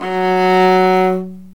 Index of /90_sSampleCDs/Roland - String Master Series/STR_Viola Solo/STR_Vla3 _ marc
STR VIOLA 03.wav